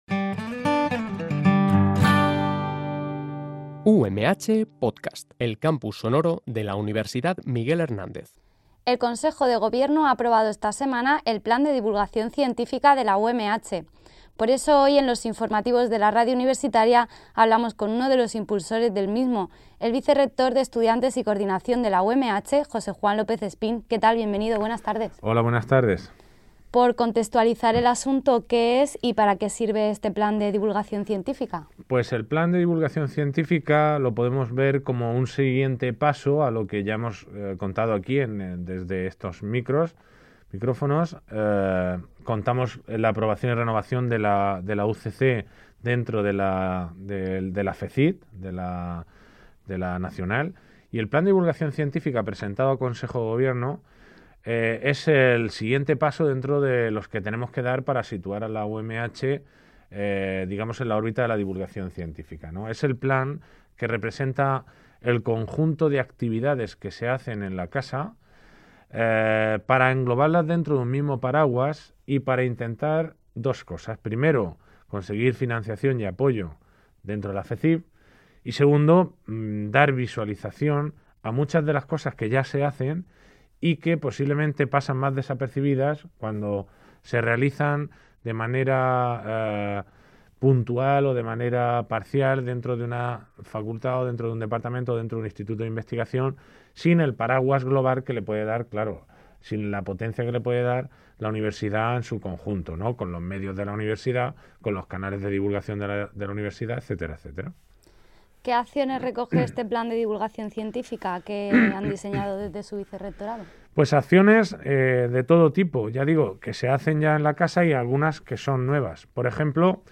Este programa de noticias se emite de lunes a viernes, de 13.00 a 13.10 h